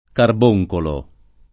carbunculo [karb2jkulo] o carbonculo [karb1jkulo] (meno com. carbuncolo [karb2jkolo] e carboncolo [